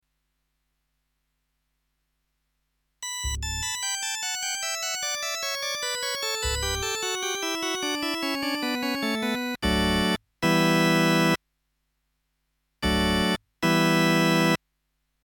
Orgel, die einen ganz eigenen Klang entwickelte.